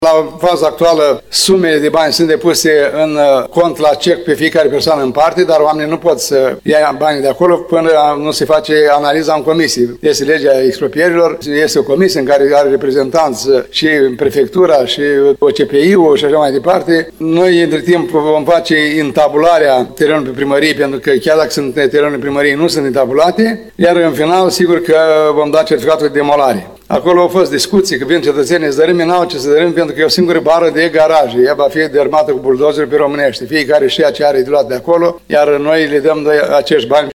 Primarul ION LUNGU a explicat care vor fi următoarele etape ale proiectului.